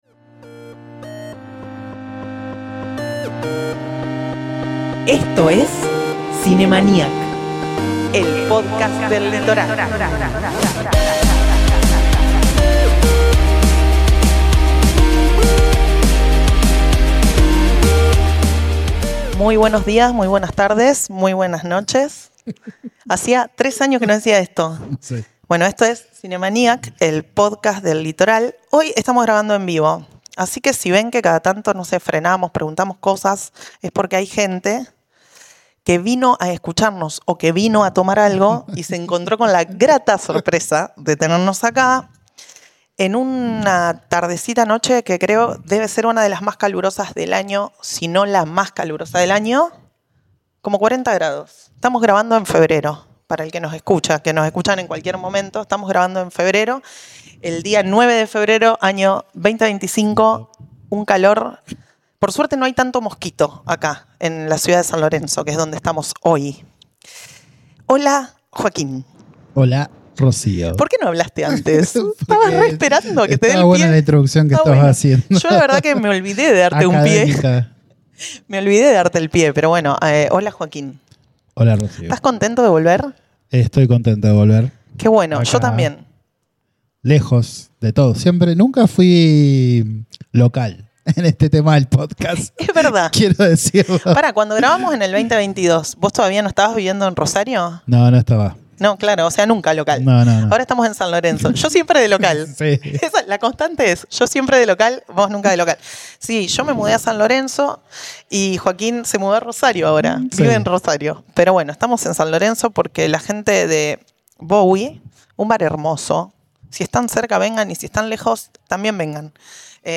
Y esta vez grabando episodios en vivo en un lugar hermoso! En el episodio de hoy: Las peliculas de David Bowie ⚡